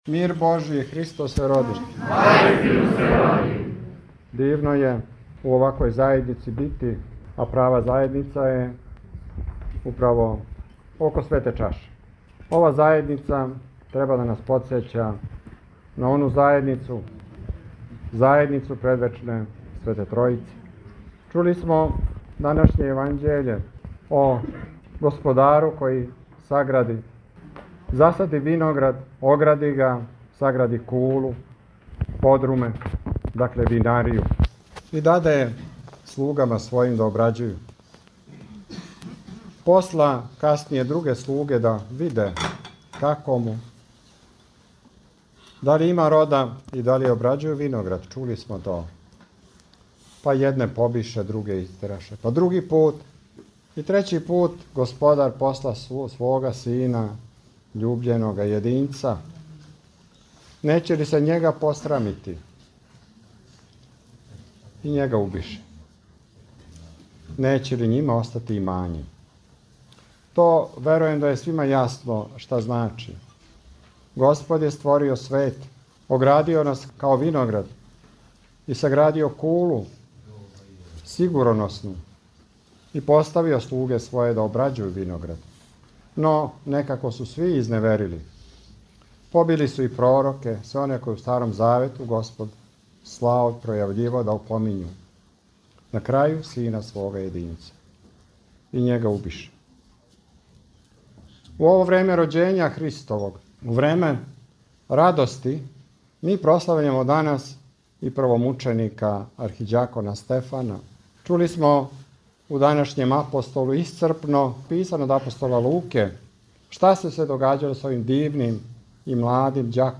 Са благословом Његове Светости Патријарха српског г. Порфирија, Његово Преосвештенство Епископ хвостански г. Алексеј служио је свету архијерејску Литургију у манастиру Сланци, на празник Светог првомученика и архиђакона Стефана, 9. јануара 2023. године.
Звучни запис беседе